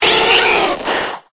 Cri de Goupix dans Pokémon Épée et Bouclier.